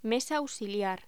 Locución: Mesa auxiliar
voz